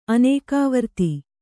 ♪ anēkāvarti